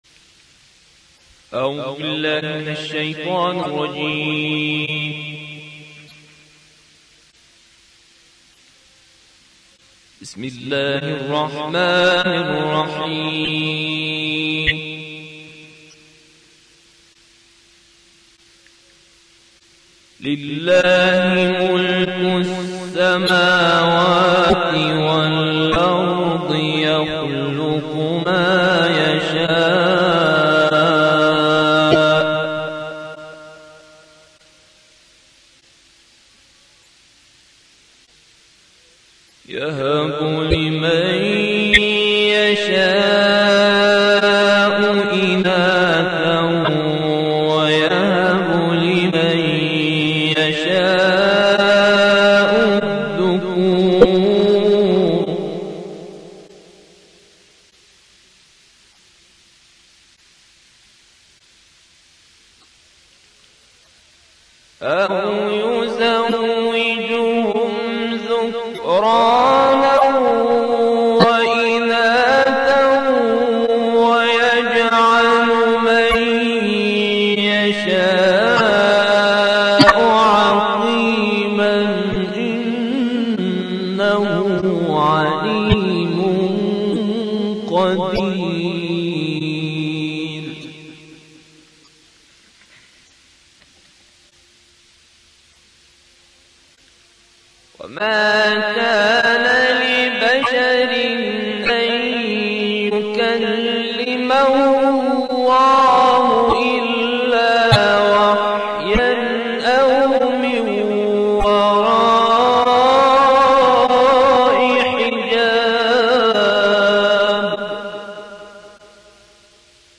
این تلاوت در دهه هفتاد شمسی اجرا شده است.
تلاوت در دهه هفتاد